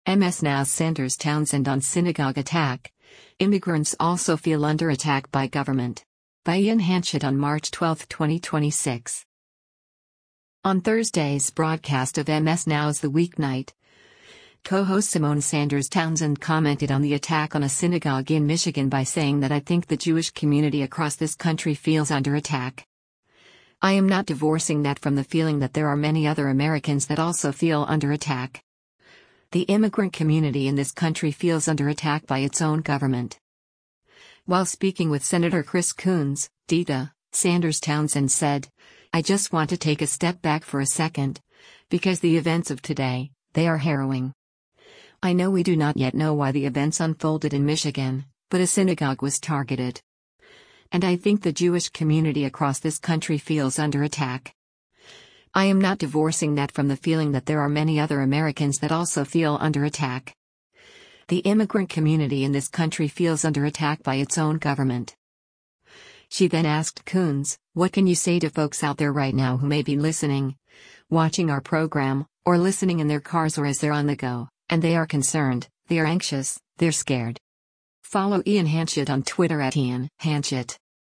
On Thursday’s broadcast of MS NOW’s “The Weeknight,” co-host Symone Sanders Townsend commented on the attack on a synagogue in Michigan by saying that “I think the Jewish community across this country feels under attack. I am not divorcing that from the feeling that there are many other Americans that also feel under attack. The immigrant community in this country feels under attack by its own government.”